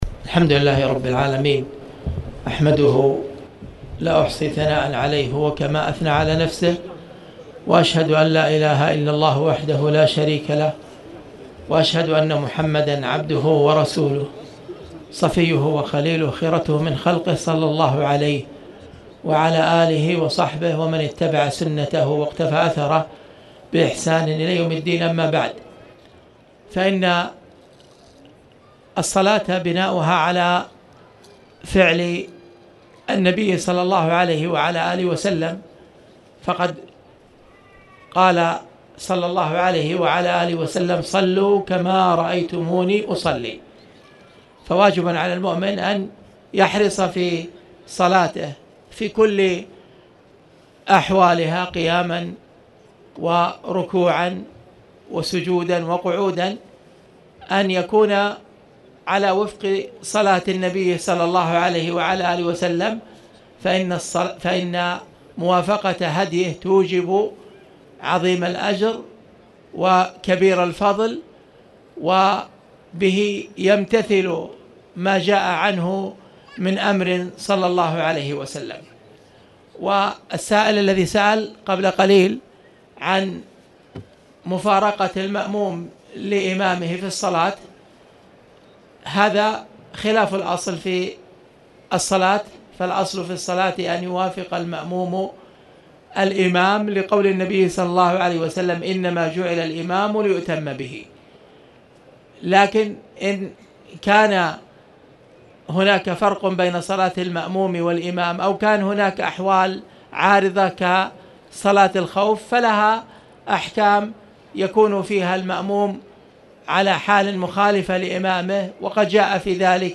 تاريخ النشر ٢٩ ربيع الثاني ١٤٣٨ هـ المكان: المسجد الحرام الشيخ